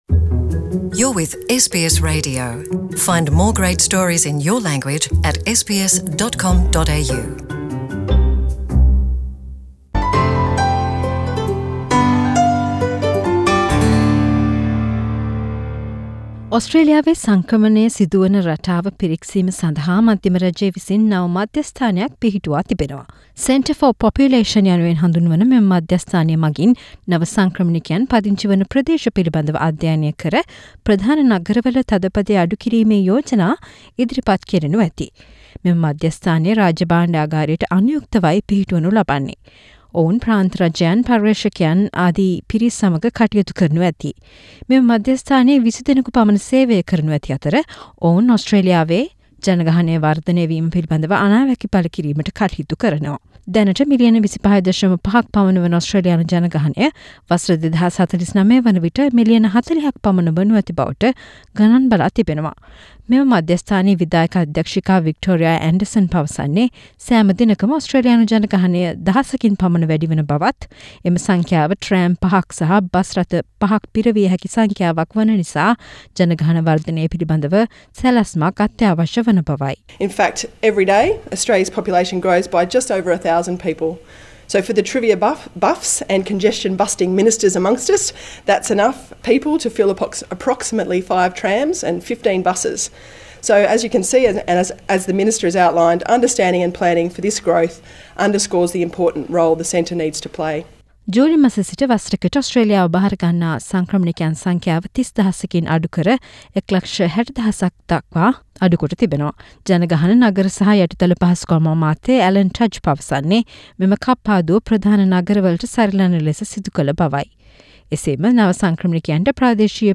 Minister for Population, Cities and Urban Infrastructure, Alan Tudge speaking about the Centre for Population. Source: SBS